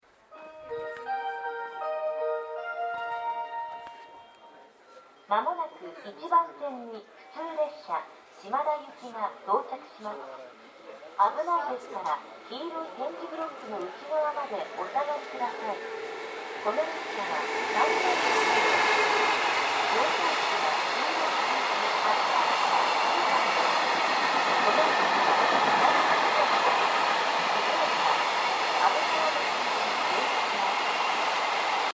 この駅では接近放送が設置されています。スピーカーは数か所に設置されており音質はいいと思います。
接近放送普通列車　島田行き接近放送です。音量にご注意ください。